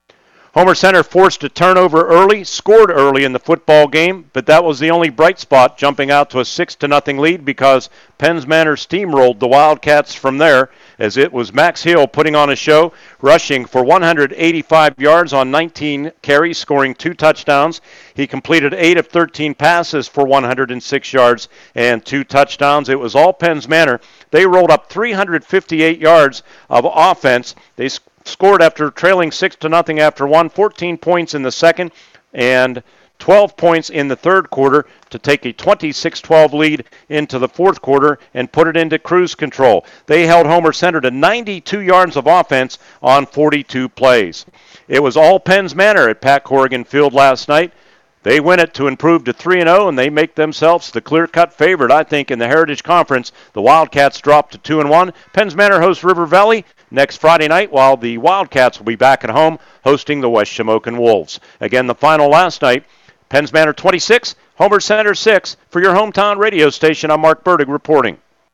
recap on WCCS FM 101.1 and AM 1160.